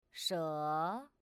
Pronounce "Snake" in Chinese
shé in Chinese, with a rising second tone.